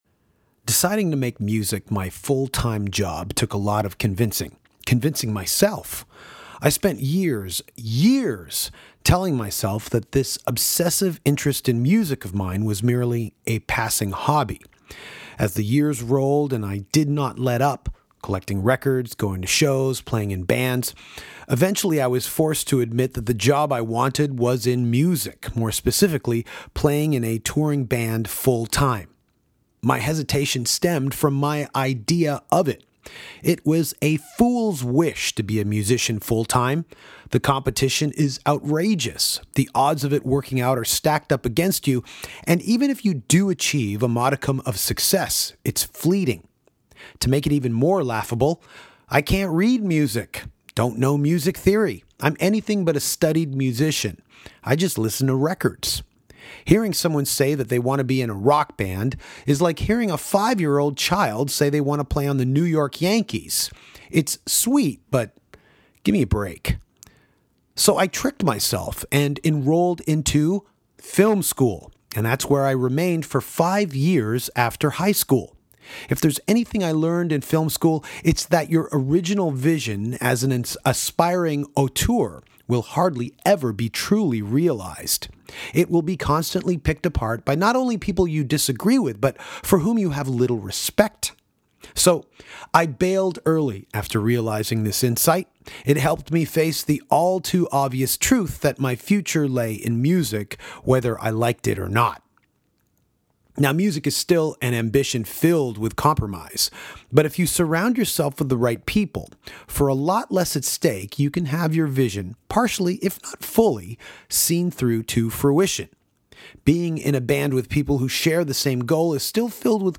Legendary filmmaker, Jonas Åkerlund, sits in on the podcast with Danko to talk about the making of his Lords Of Chaos film, Amir Chamdin, the city of Toronto and Polar, his new upcoming film with Mads Mikkelsen.